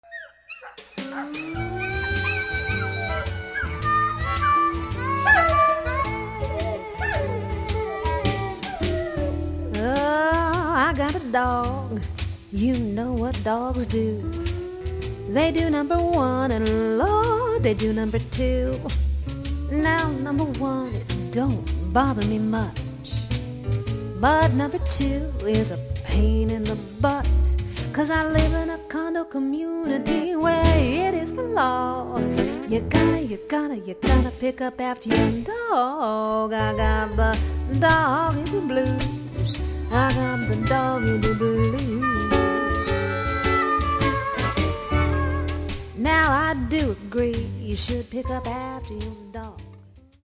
--humorous acoustic music